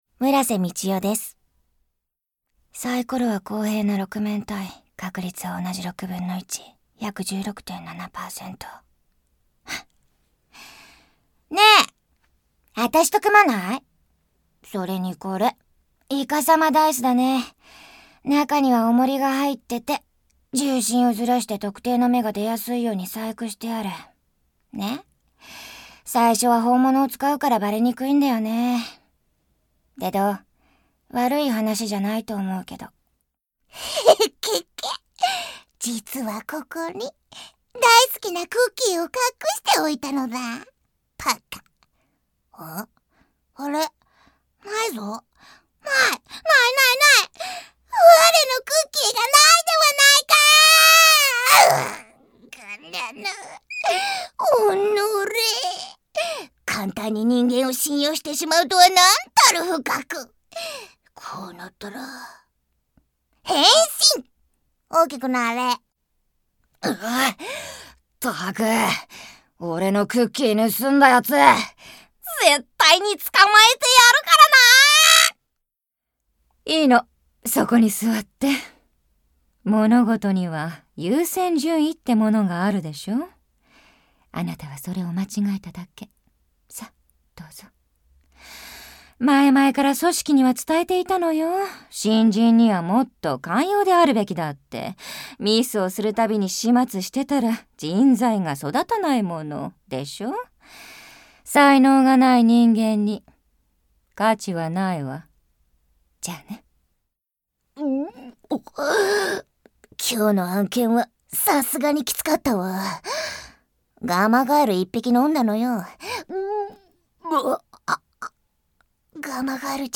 マウスプロモーションの所属タレントをサンプルボイス付きでご紹介します。